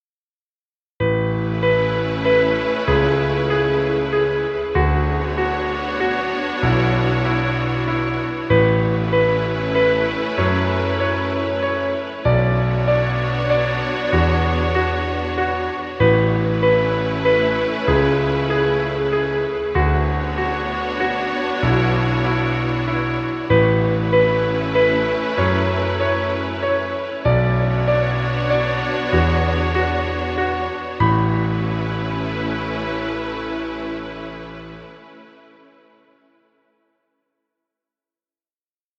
Romantic music. Background music Royalty Free.